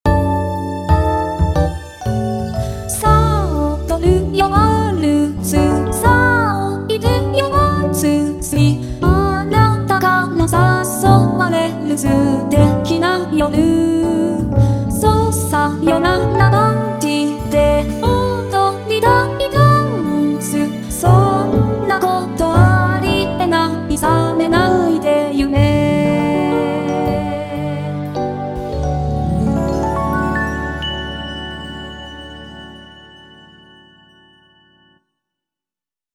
巡音ルカに歌ってもらった「メヌエット」 (オマケ: VSQと説明書)
minuet_vocalo-p.mp3